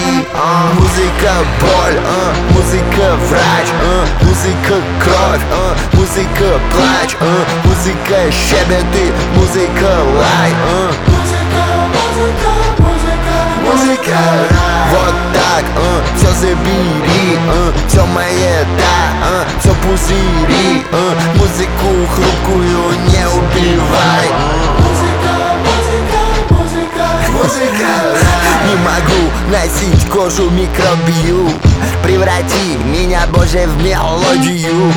Жанр: Хип-Хоп / Рэп / Русский рэп / Русские